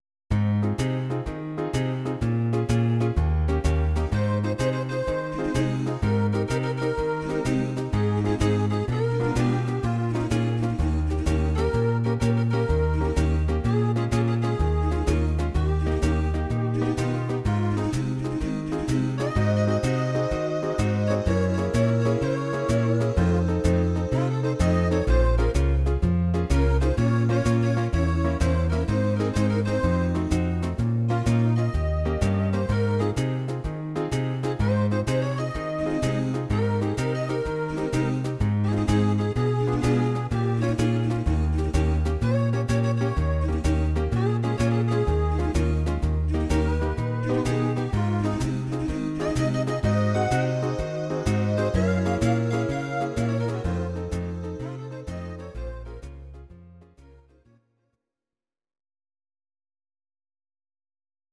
Audio Recordings based on Midi-files
Pop, Oldies, 1950s